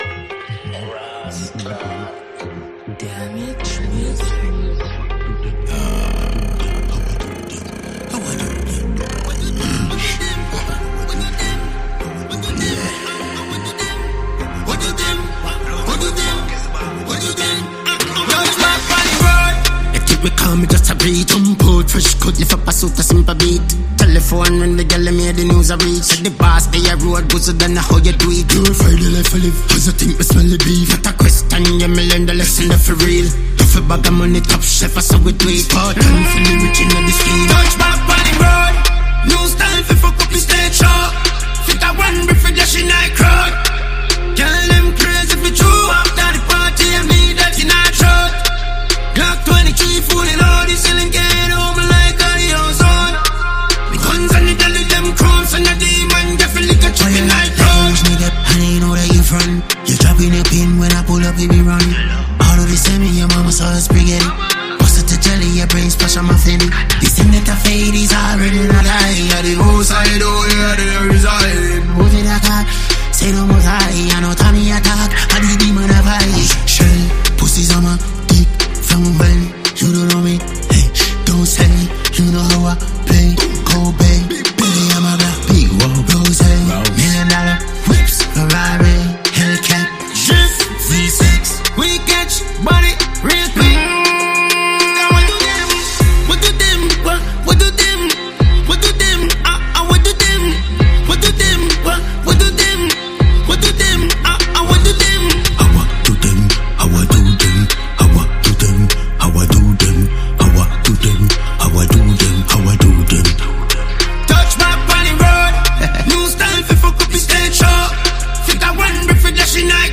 Jamaican award winning dancehall act